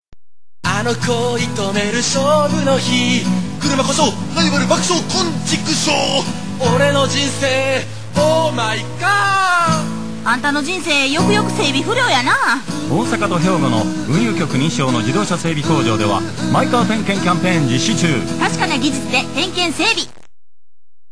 (ちょっとシャウト系の感じで歌う)〜
女性(ライブの観客っぽく合いの手をうつ)